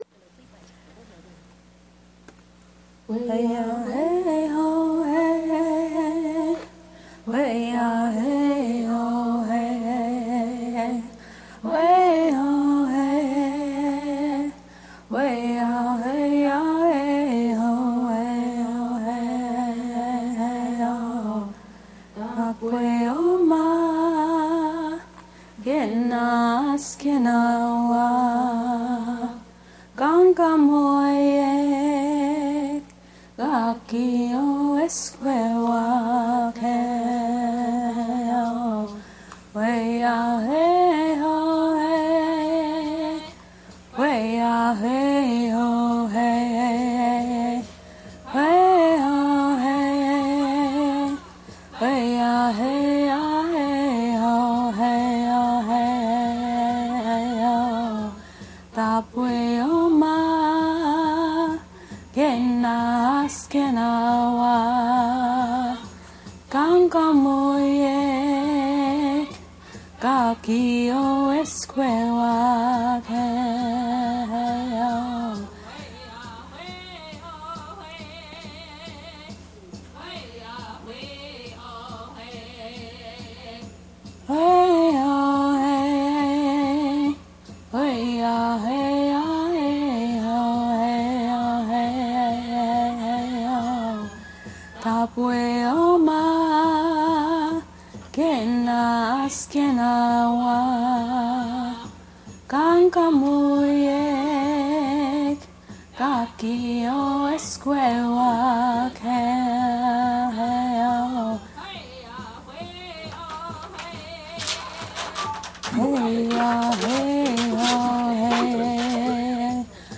inspired by your recent ethnic music posts. sorry for the scratching & clipping.